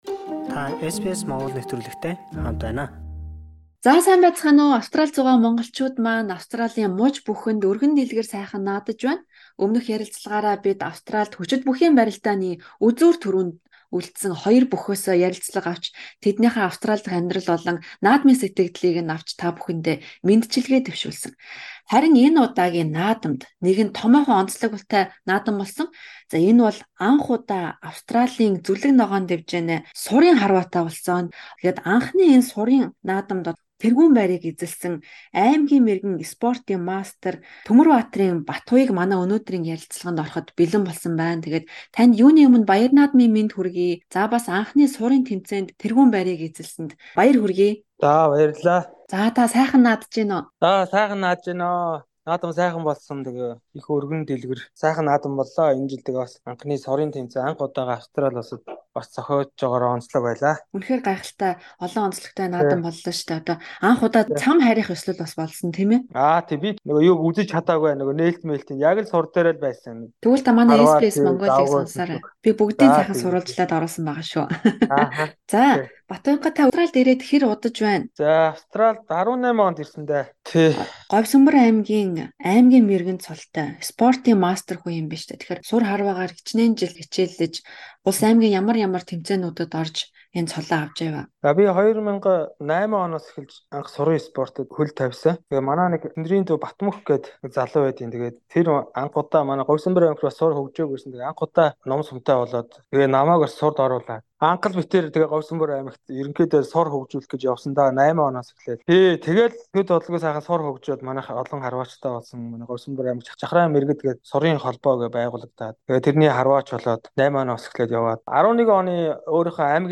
Ингээд түүний наадмын сэтгэгдэл, Монгол сур харвааны тухай хийсэн ярилцлагыг хүлээн авна уу.